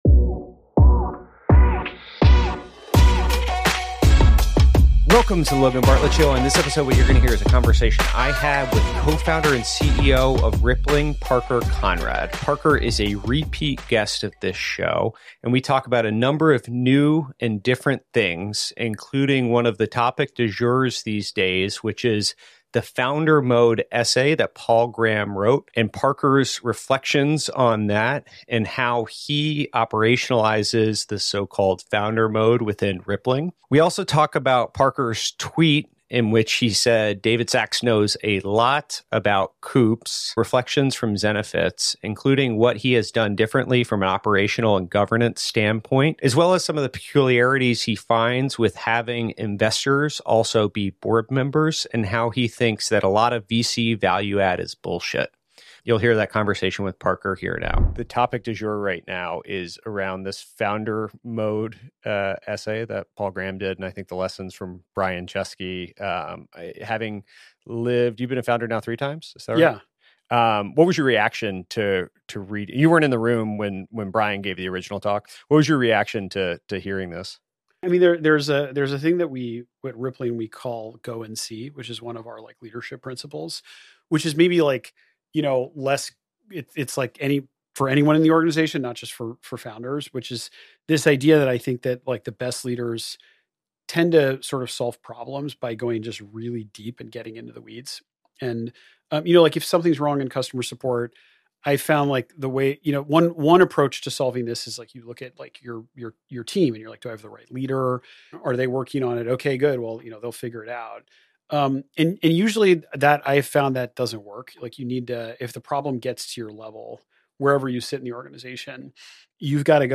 In his second appearance on the podcast, Parker and I discussed Paul Graham's "founder mode" essay and how he operationalizes it at Rippling. He also reflected on the key lessons he's learned throughout his career, highlighting the differences in how he ran Zenefits compared to Rippling today. Additionally, Parker shared his candid views on the challenges of having investors as board members and why he believes much of VC "value add" is overrated.